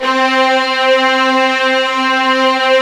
Index of /90_sSampleCDs/Roland L-CD702/VOL-1/CMB_Combos 2/CMB_Bryt Strings